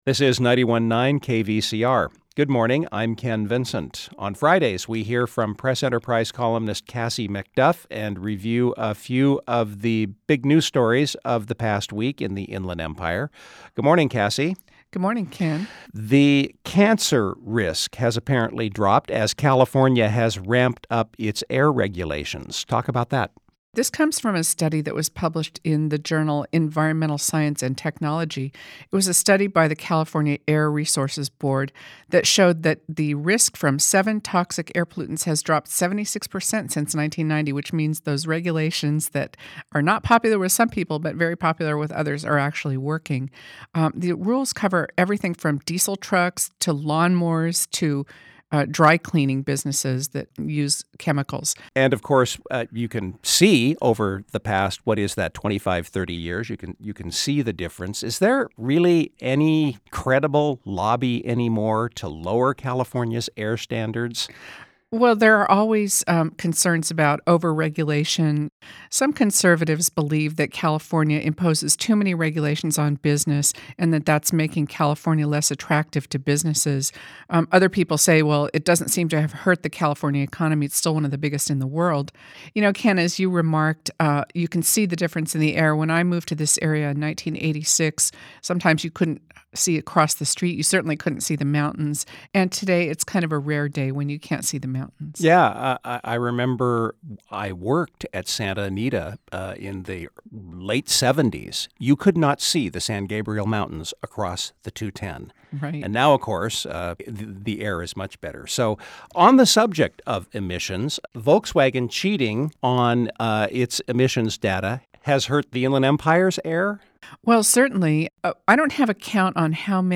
Local Civic Affairs